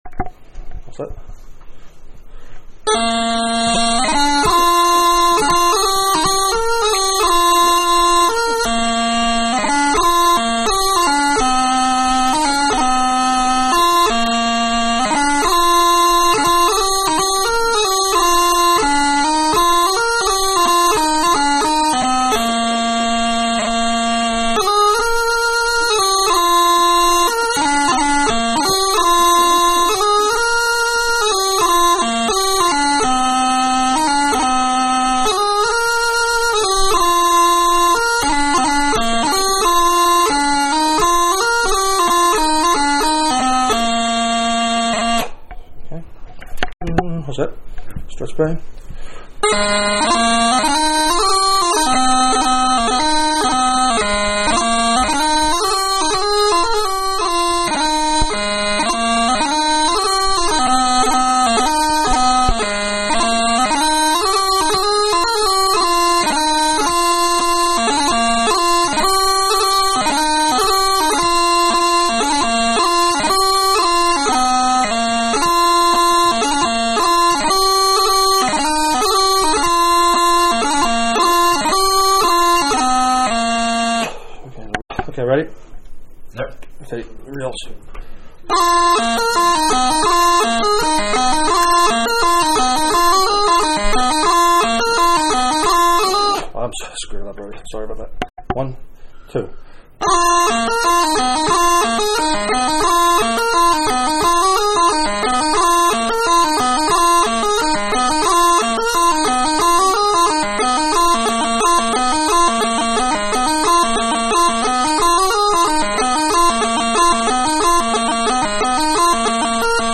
March 84 bpm
Strathspey 112 bpm
Reels 87 bpm
Slow Air 80 bpm
Jigs 115 bpm